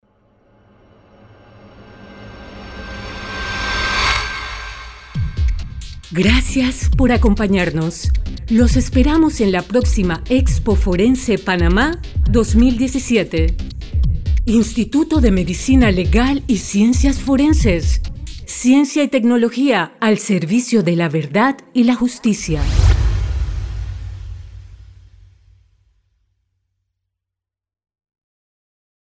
locutora de voz sensual,tono grave,medio y agudo,para publicidad,audiobooks, e learning,documentales,películas,otros.
kastilisch
Sprechprobe: Industrie (Muttersprache):